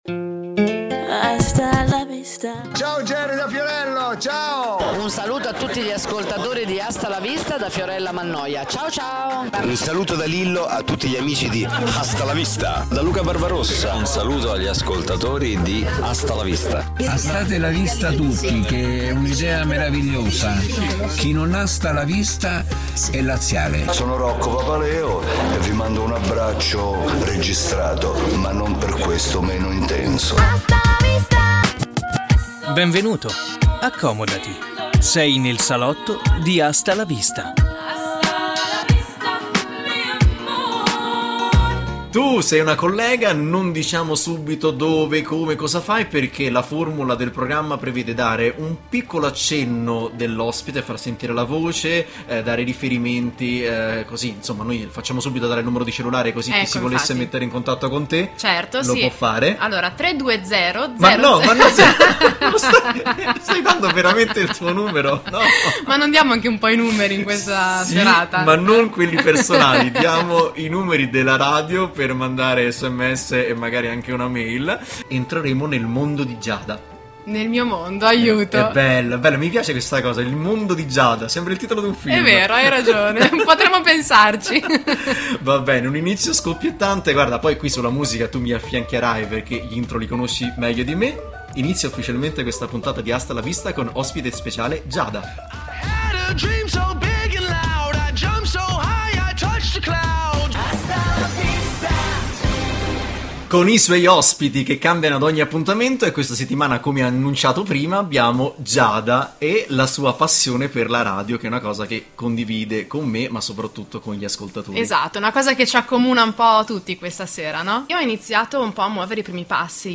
La caratteristica saliente del programma è la presenza in studio di ospiti sempre diversi che si cimentano in una co-conduzione, sperimentando il piacere e l’emozione di fare radio: oltre alla musica, protagonista è il racconto delle esperienze di vita dell’ospite.